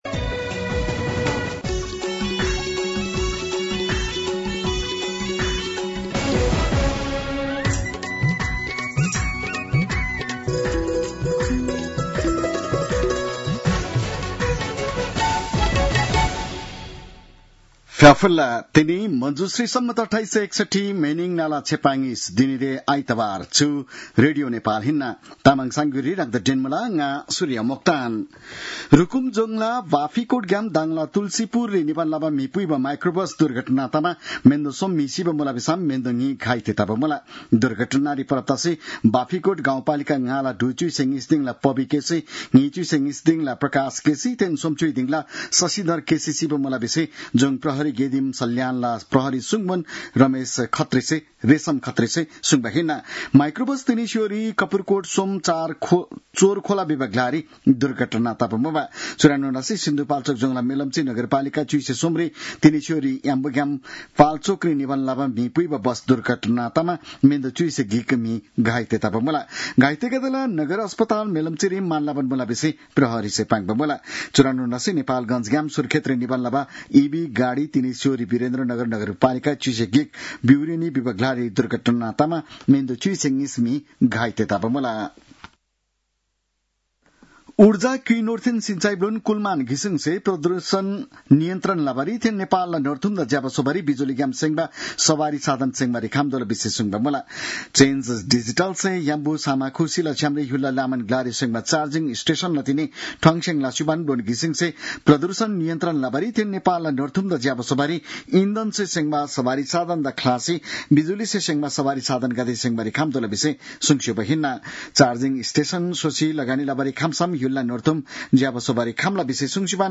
तामाङ भाषाको समाचार : १२ असोज , २०८२